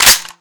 Index of /server/sound/weapons/brightmp5
boltslap.mp3